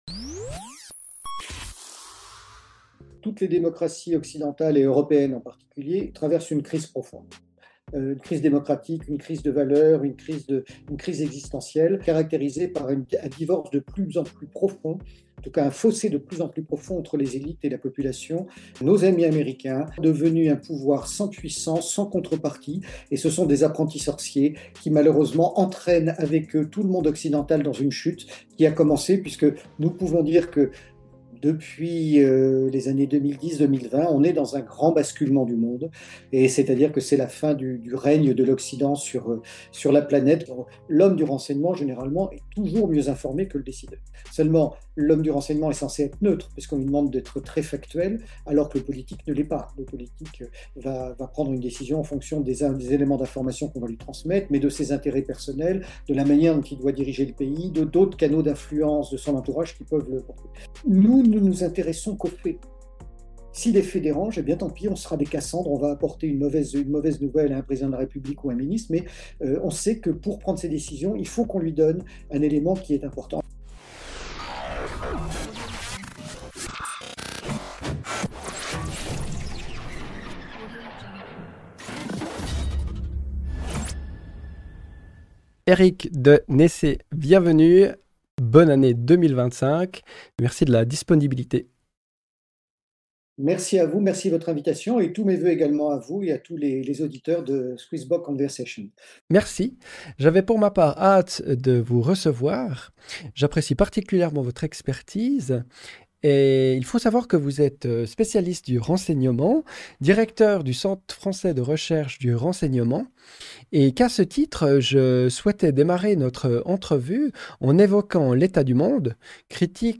Dans cette nouvelle conversation